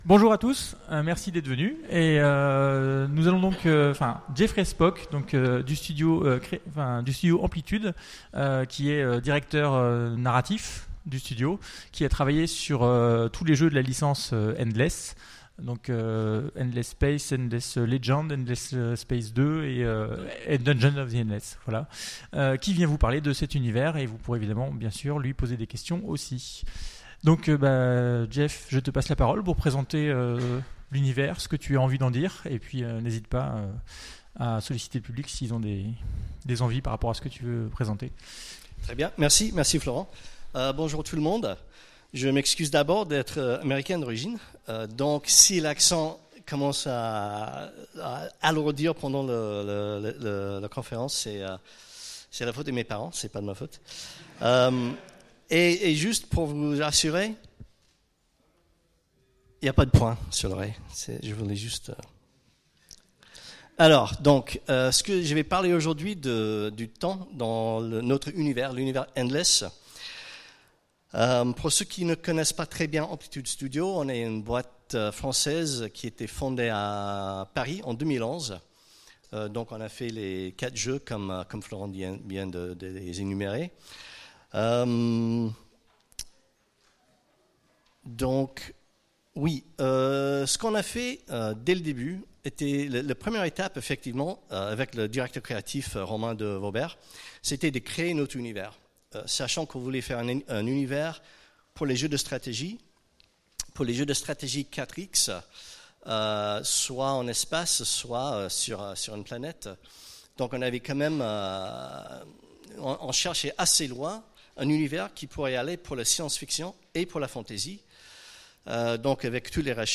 Utopiales 2017 : Conférence Chronologies chronophages dans l’univers Endless